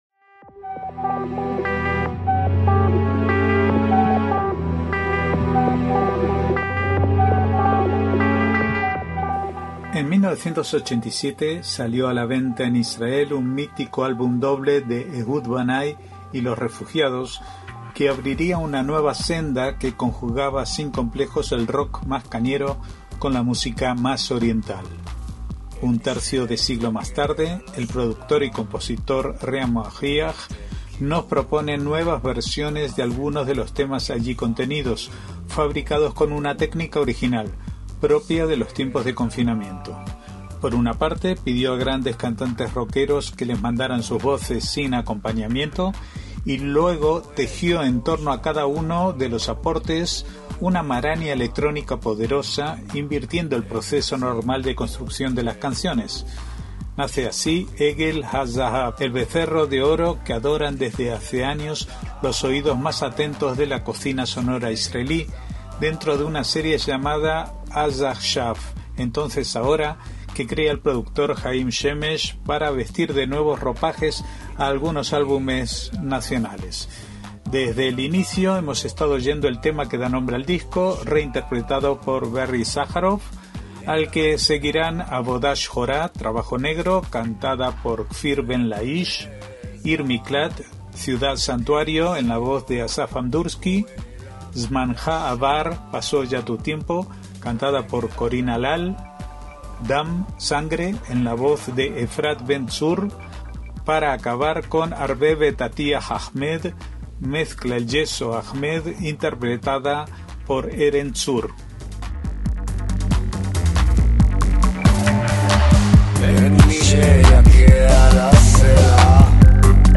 Por una parte, pidió a grandes cantantes rockeros que les mandaran sus voces sin acompañamiento, y luego tejió en torno a cada uno de los aportes una maraña electrónica poderosa, invirtiendo el proceso normal de construcción de las canciones.